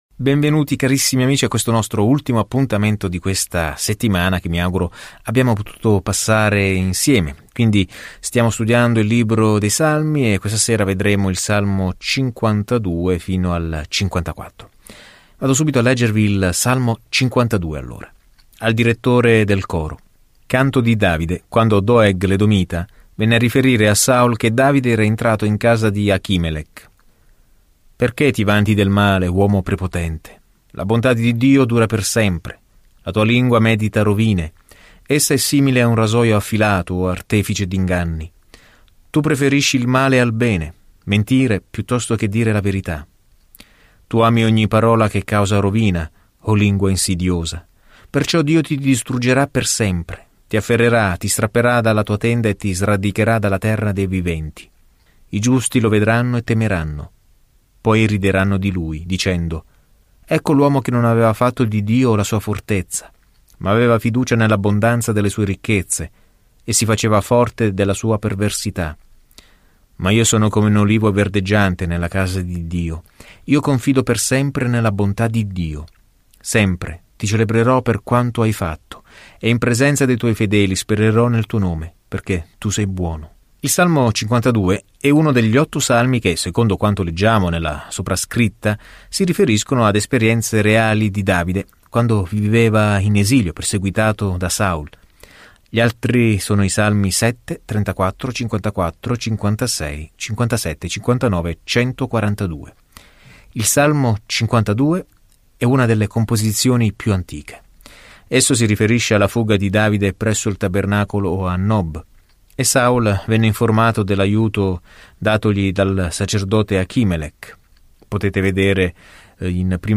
Scrittura Salmi 52:1-9 Salmi 53:1-6 Salmi 54:1-7 Giorno 29 Inizia questo Piano Giorno 31 Riguardo questo Piano I Salmi ci danno i pensieri e i sentimenti di una serie di esperienze con Dio; probabilmente ognuno originariamente messo in musica. Viaggia ogni giorno attraverso i Salmi mentre ascolti lo studio audio e leggi versetti selezionati della parola di Dio.